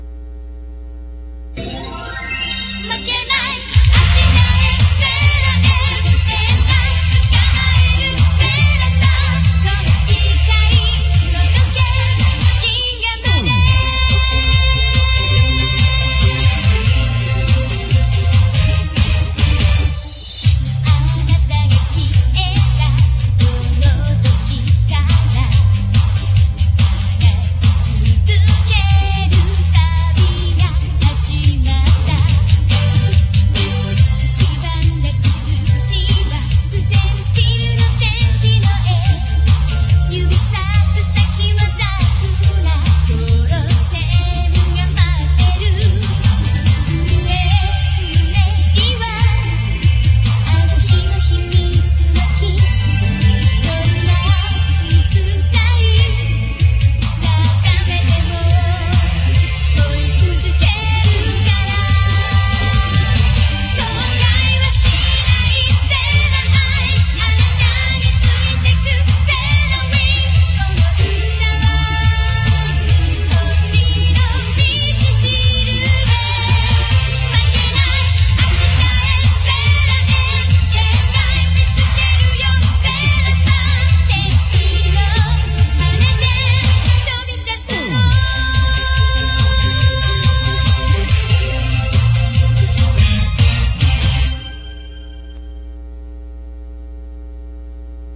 This is played at the opening os the Star season episodes.